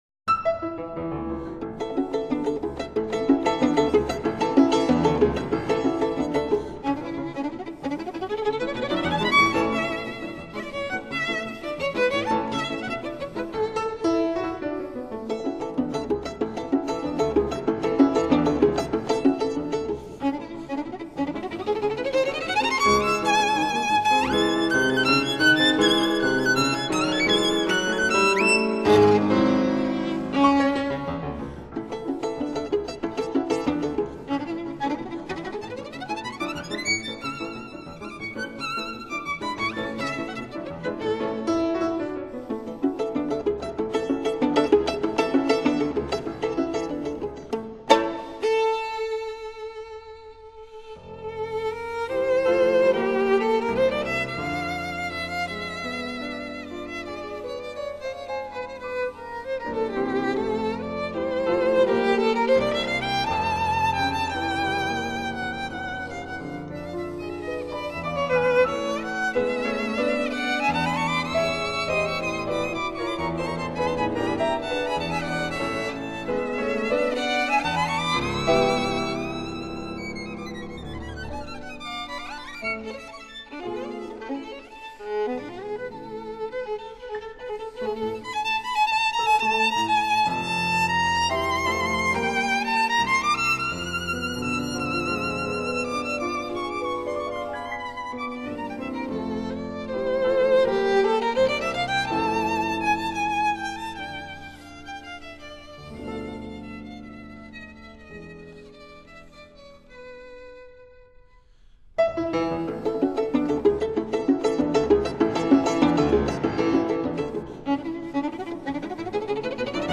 音乐类型：古典音乐